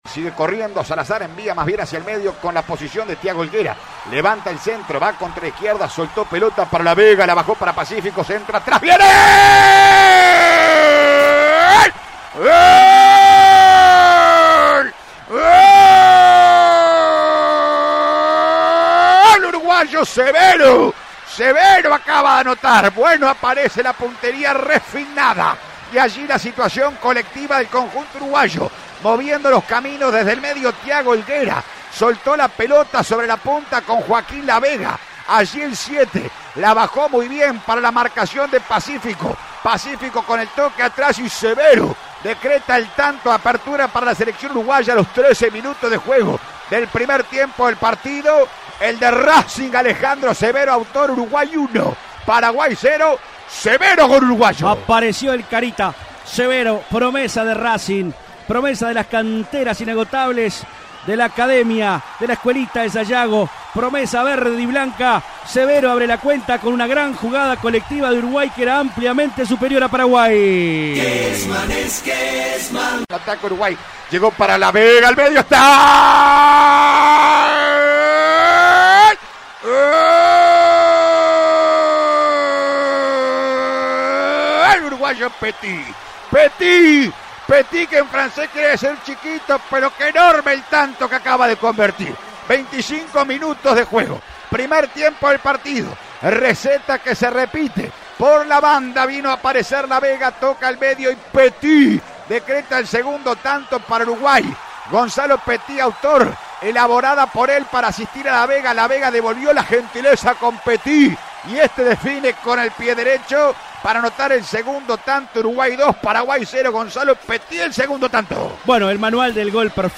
GOLES RELATADOS